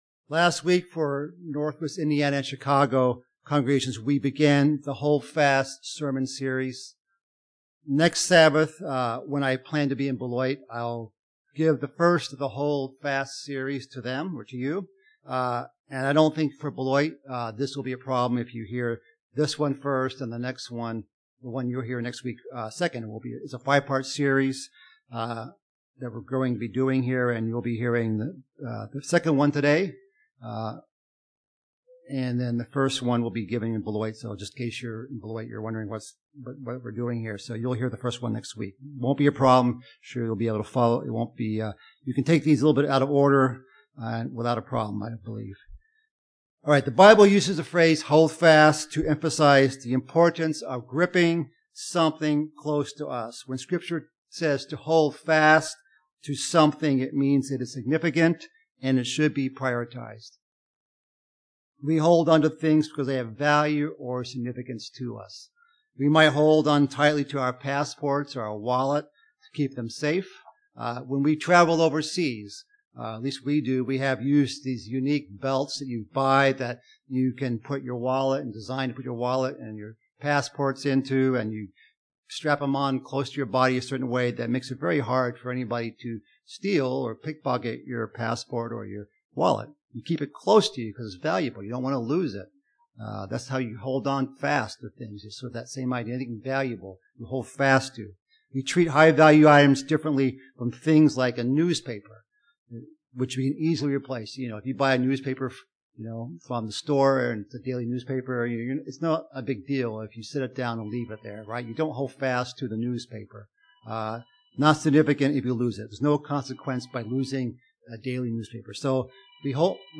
Part two in a sermon series about things we're instructed to "hold fast."